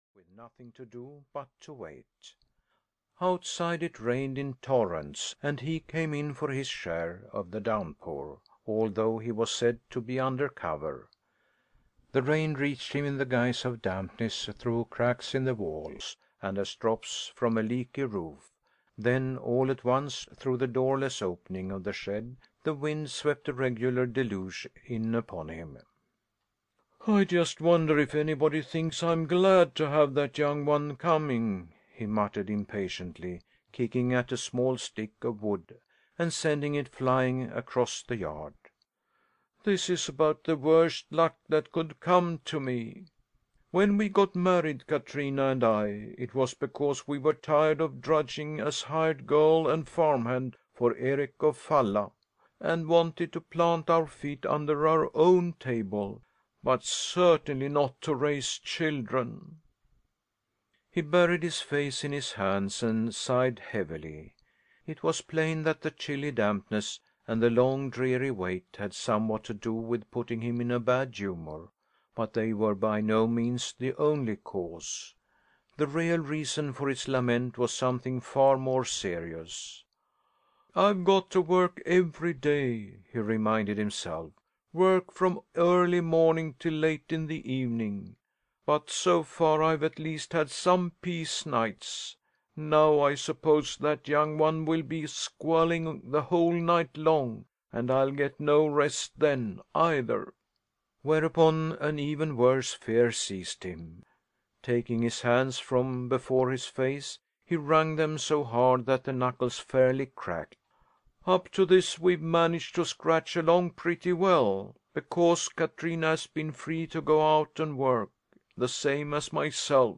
Audio knihaThe Emperor of Portugallia (EN)
Ukázka z knihy